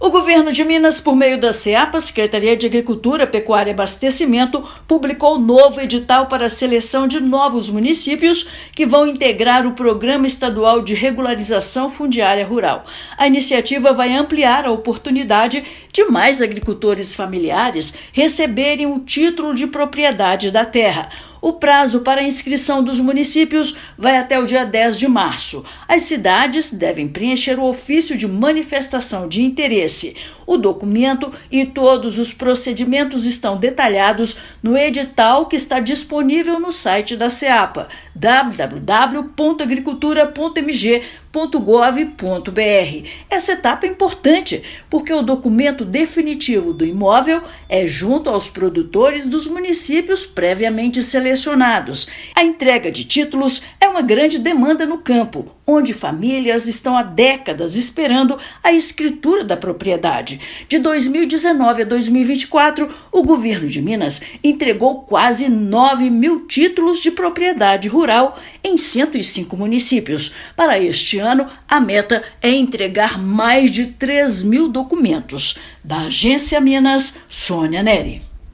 Prazo para a adesão vai até o dia 10 de março. O edital de chamamento público já está disponível no site da Secretaria de Agricultura, Pecuária e Abastecimento. Ouça matéria de rádio.